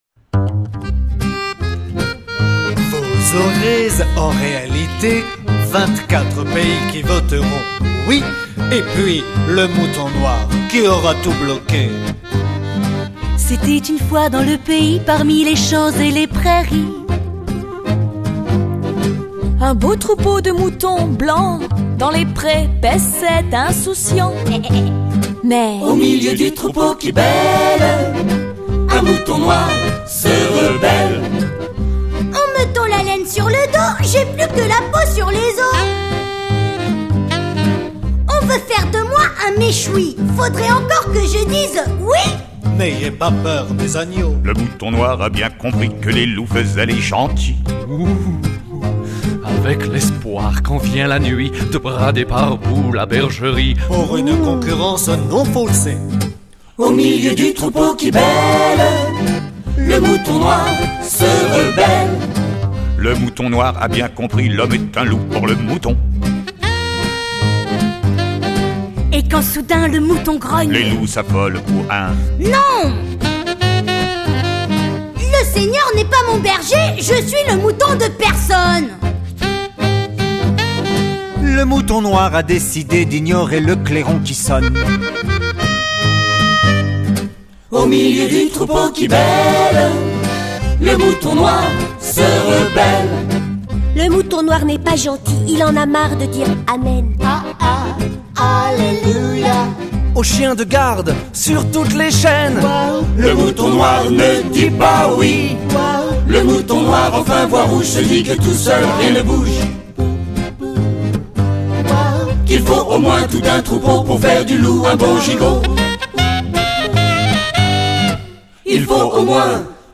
Un air festif et entrainant...!!
Nouvel arrangement (plus rapide)